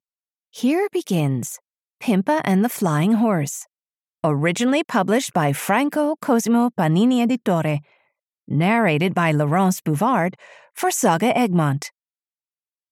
Audio knihaPimpa - Pimpa and the Flying Horse (EN)
Ukázka z knihy